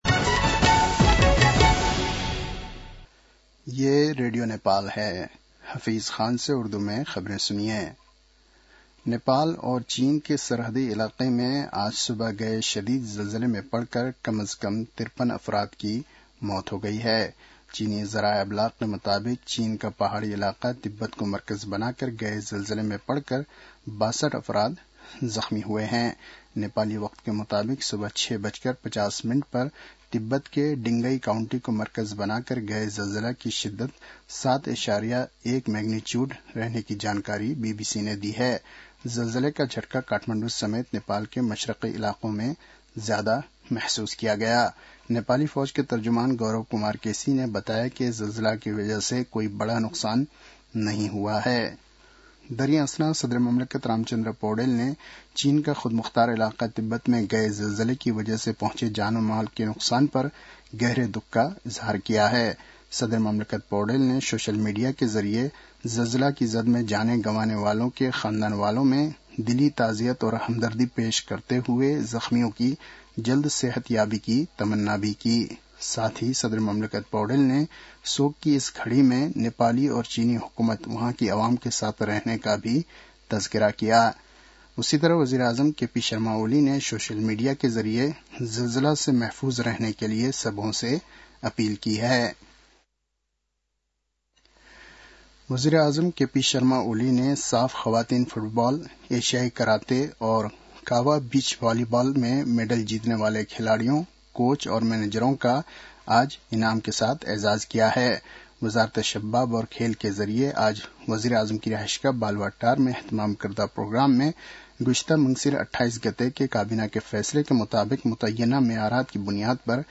उर्दु भाषामा समाचार : २४ पुष , २०८१
Urdu-news-9-23.mp3